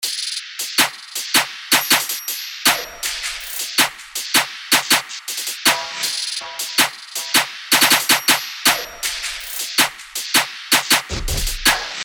四款高级音色包，共创暗黑风格，专为暗黑街头陷阱音乐和地下节拍打造。
keef_160_bpm.mp3